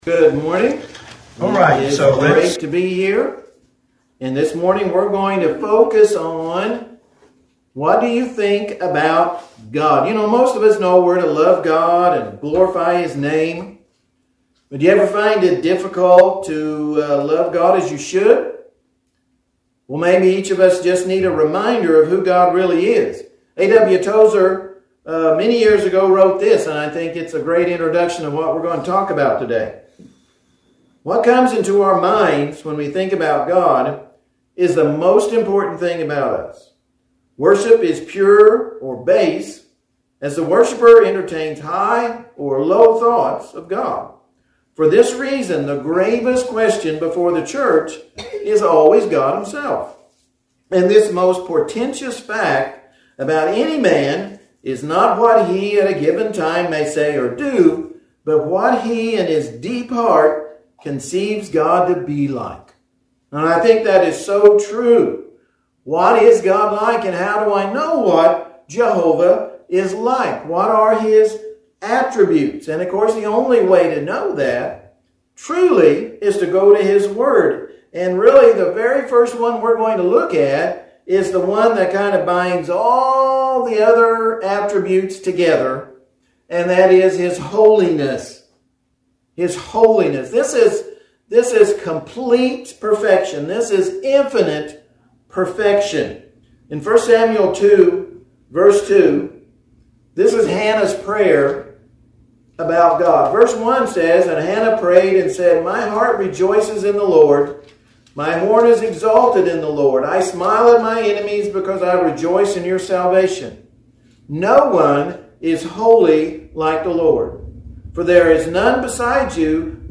A scripture-saturated sermon on God's holiness, eternity, omniscience, omnipotence, and justice, calling believers to deeper reverence, obedience, and worship.